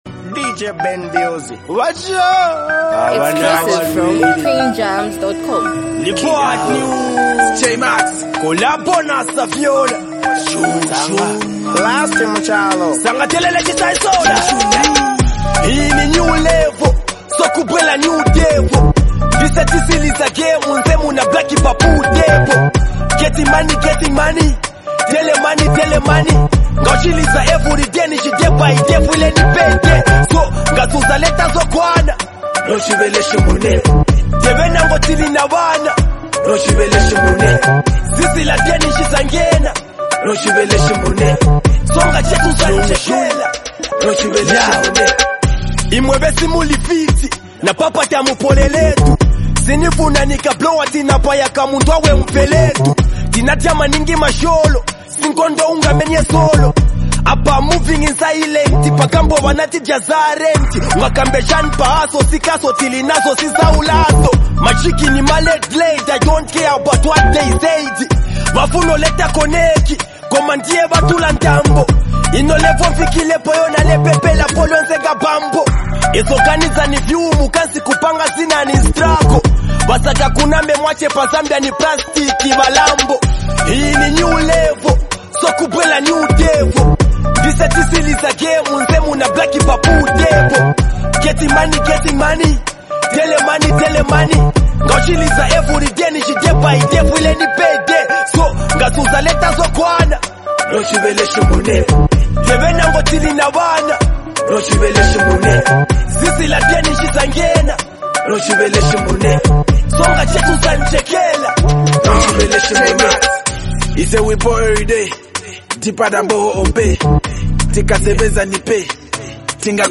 Highly talented act and super creative rapper